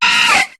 Cri de Chenipotte dans Pokémon HOME.